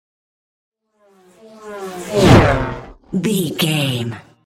Pass by fast speed engine
Sound Effects
pass by
car
vehicle